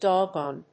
音節dog・gone 発音記号・読み方
/dάggˈɔːn(米国英語), dˈɔgɔn(英国英語)/